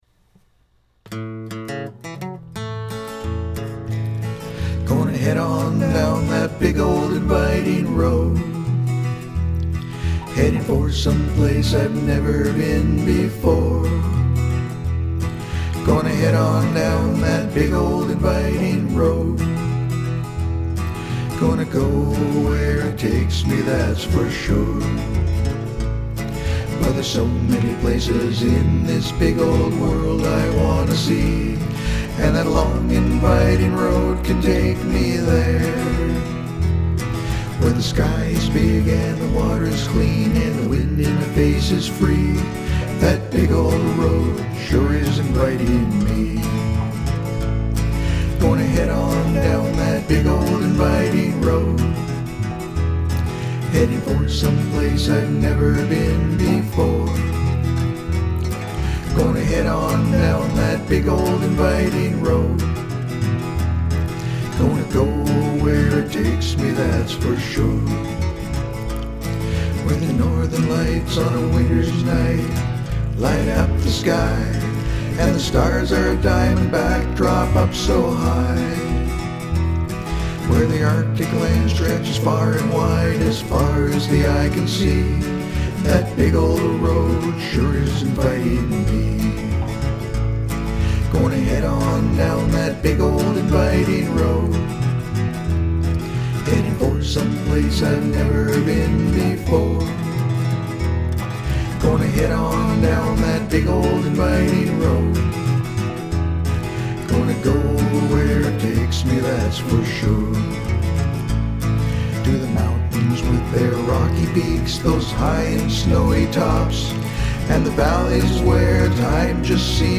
All vocals and instrumentation is by me.